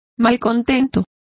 Complete with pronunciation of the translation of discontented.